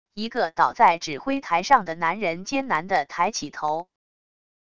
一个倒在指挥台上的男人艰难地抬起头wav音频